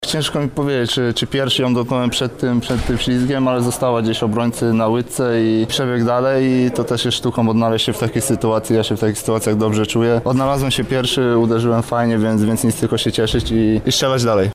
• komentował sytuację Karol Czubak.